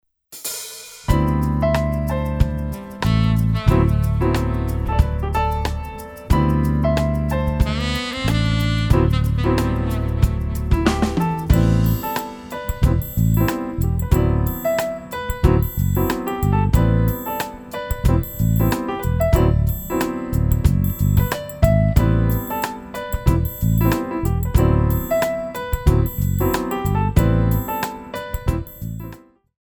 4 bar intro
moderato
Jazz (Smooth Jazz) / Tap